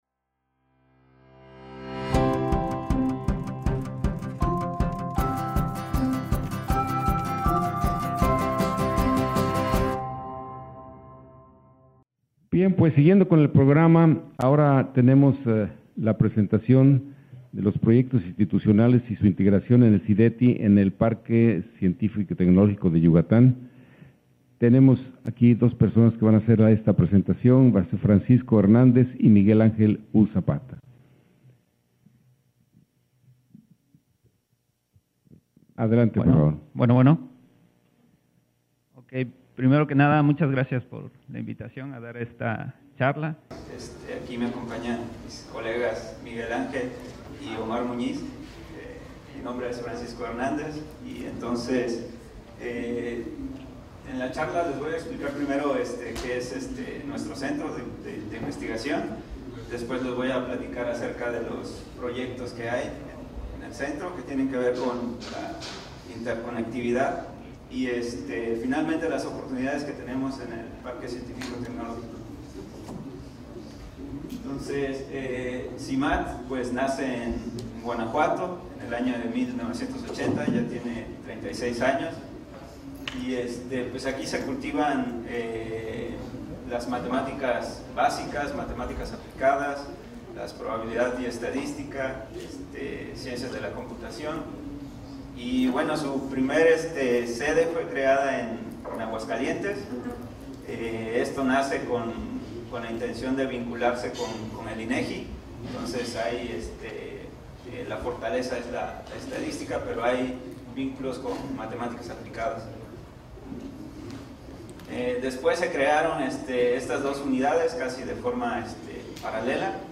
Audio de las conferencias